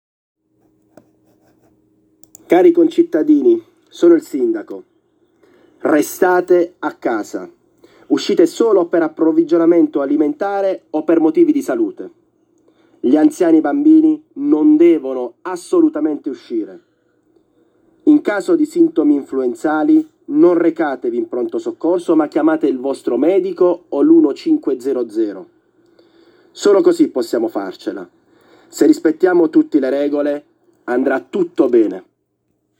Sta circolando per le vie della città un’autovettura con altoparlante che trasmette un messaggio audio del sindaco di Monopoli Angelo Annese, nel quale il Primo Cittadino invita tutti a “restare a casa” e rassicurando che “andrà tutto bene”.